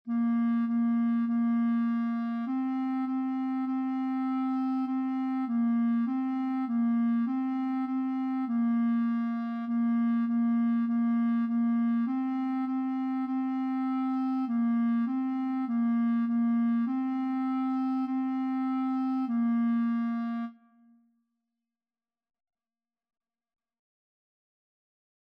2/4 (View more 2/4 Music)
Bb4-C5
Clarinet  (View more Beginners Clarinet Music)
Classical (View more Classical Clarinet Music)